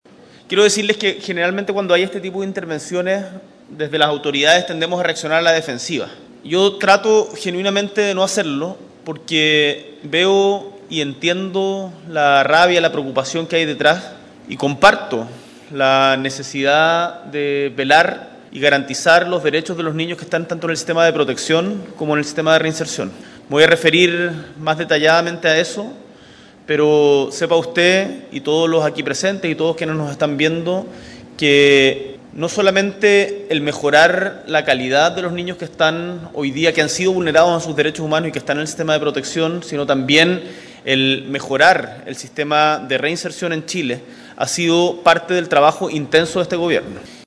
La ceremonia se realizó en el Centro Cultural Matucana 100 y contó con la presencia del presidente Gabriel Boric.
En tanto, antes de iniciar su discurso, el mandatario fue increpado por un asistente, quien lo acusó de haberle fallado “a los niños de Chile”. Tras escuchar la intervención del hombre, el presidente agradeció las palabras y aseguró que se referiría al tema en particular durante su discurso.